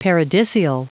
Prononciation du mot paradisial en anglais (fichier audio)
Prononciation du mot : paradisial